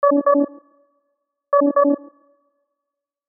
Motogp Crash Efeito Sonoro: Soundboard Botão
Sound Effects Soundboard0 views